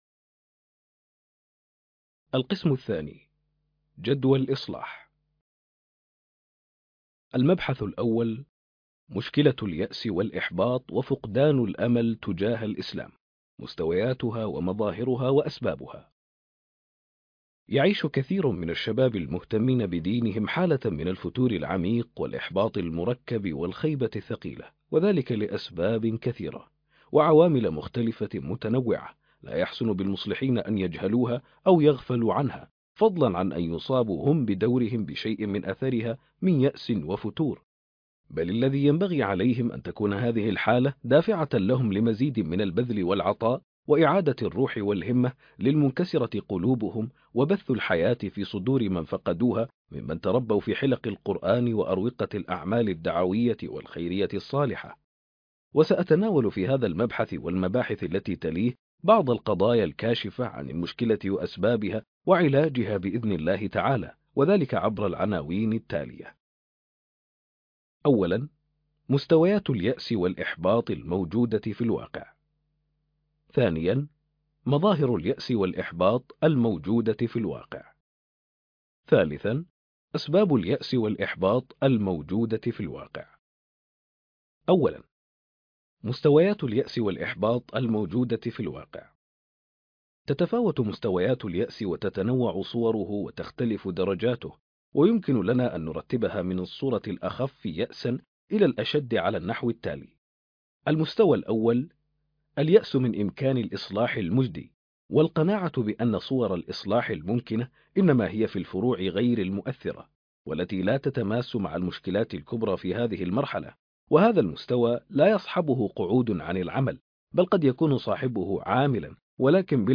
بوصلة المصلح كتاب صوتي ٥٢